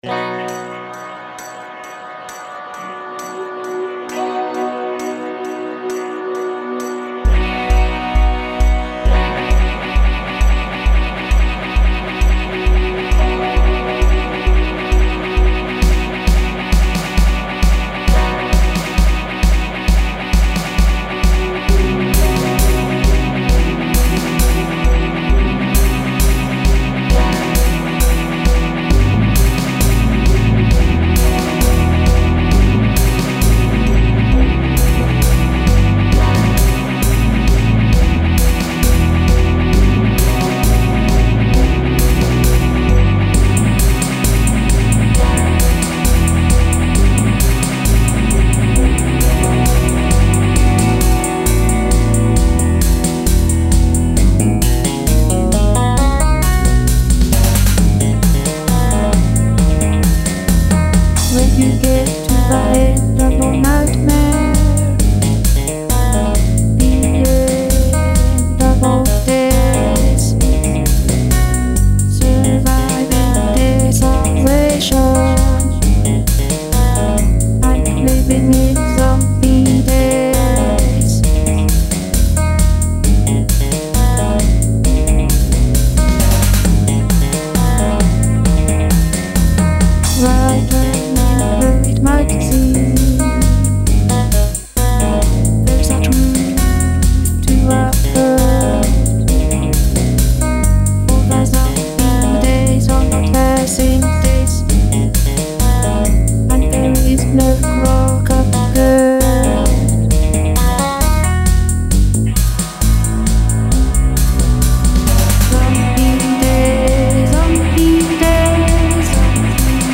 The "song" has a vocal element, but lyrics as such are optional.